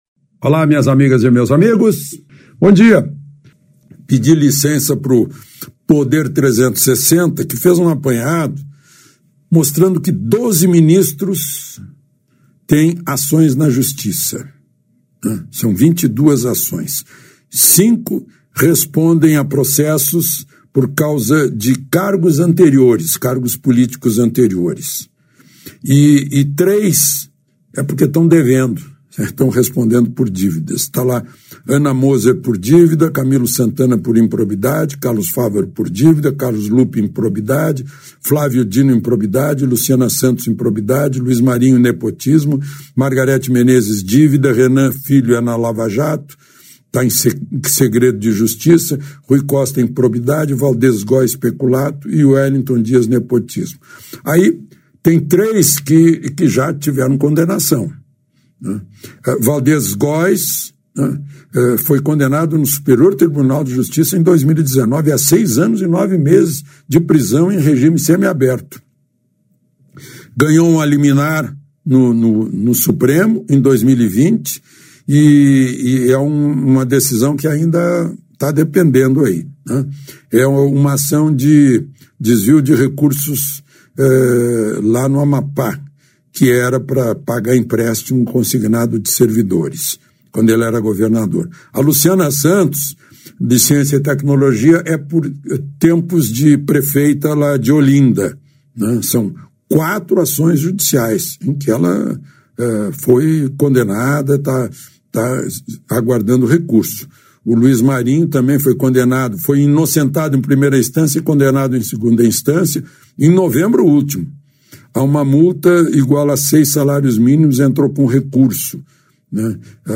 Ouça abaixo o comentário do jornalista Alexandre Garcia sobre o assunto: